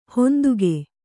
♪ honduge